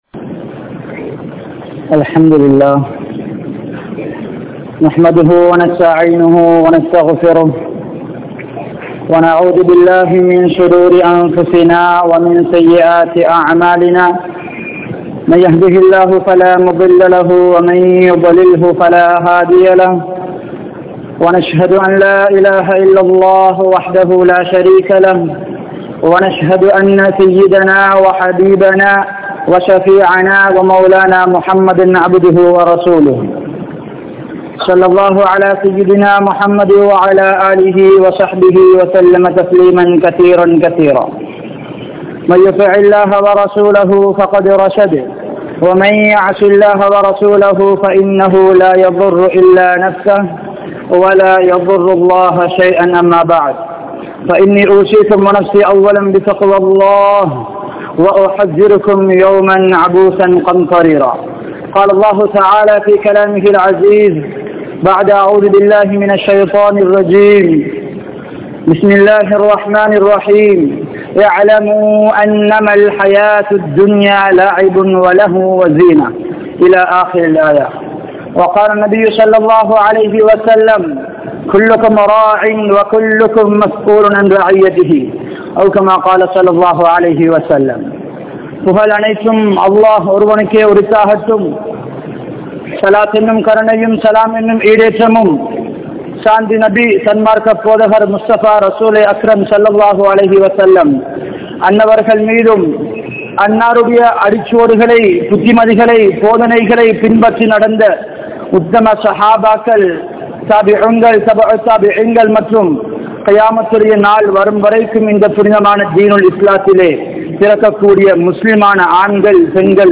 Siraarhalai Seeralikkum Cartoon (சிறார்களை சீரழிக்கும் கார்டூன்) | Audio Bayans | All Ceylon Muslim Youth Community | Addalaichenai